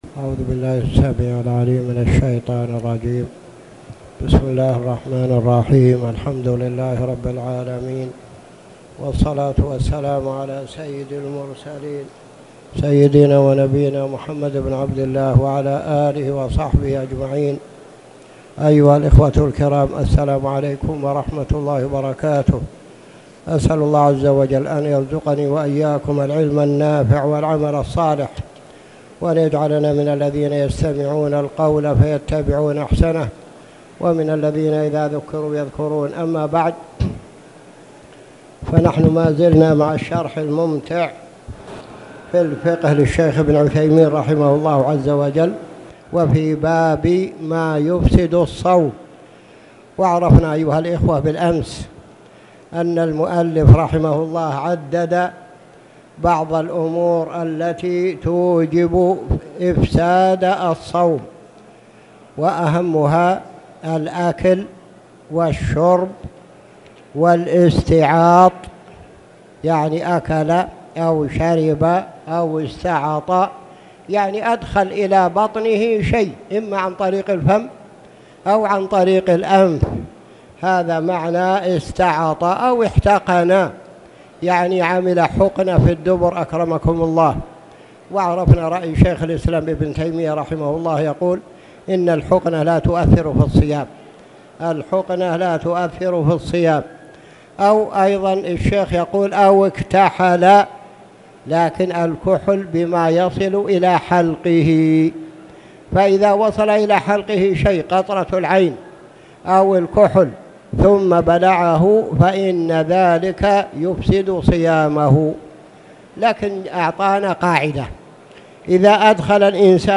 تاريخ النشر ٢٦ رجب ١٤٣٨ هـ المكان: المسجد الحرام الشيخ